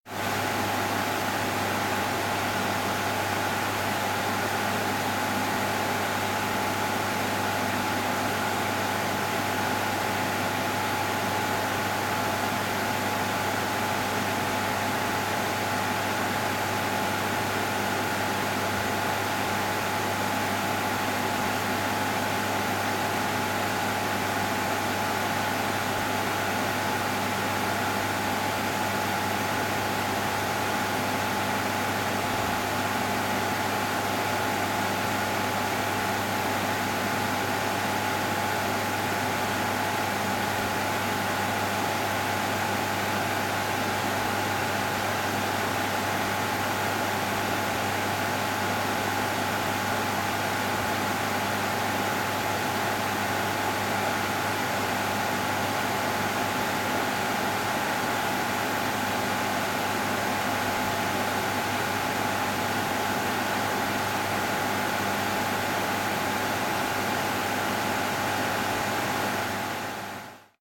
server-room.mp3